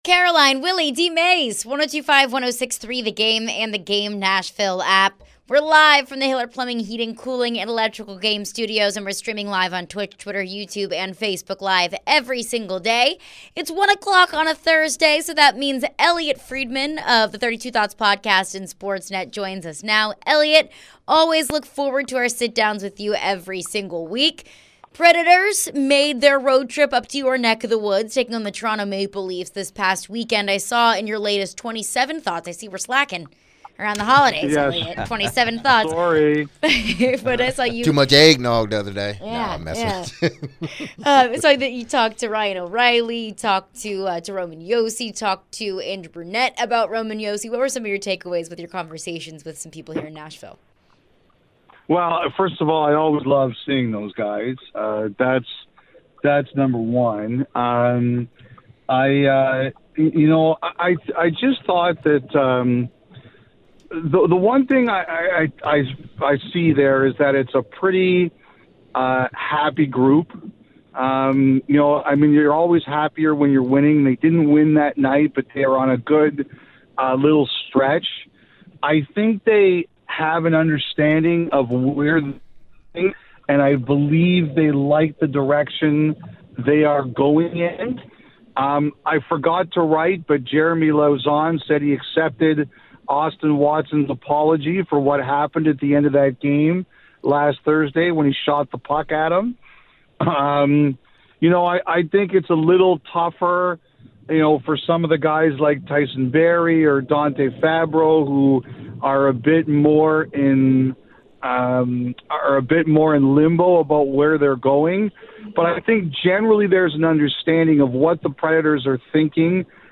the guys talk with Elliotte Friedman with Sportsnet Hockey Night in Canada. Elliotte discusses the Nashville Predators and their recent success. Elliotte shared his thoughts from around the league.